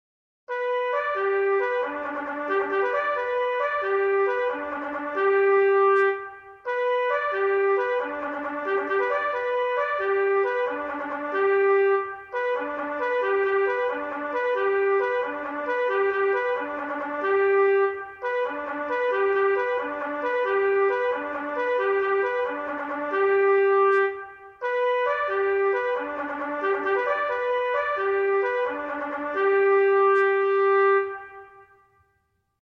Traditional Bugle Call